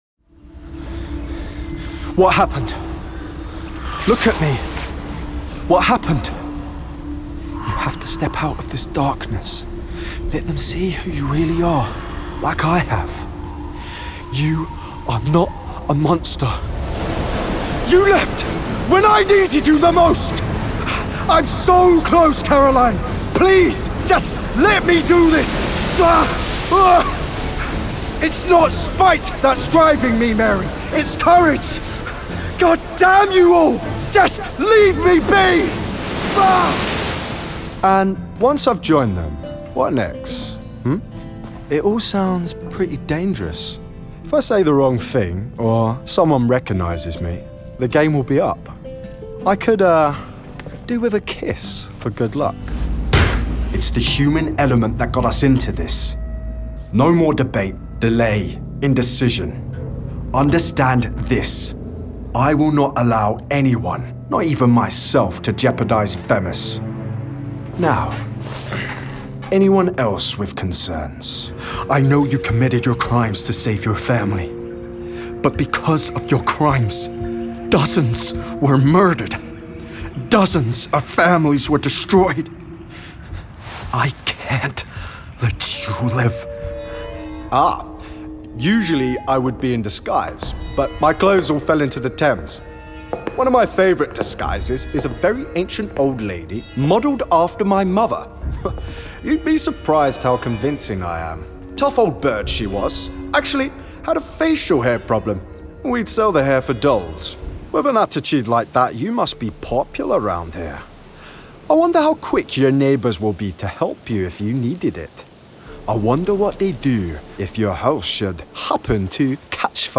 Gravitas, urban and cool. Actor.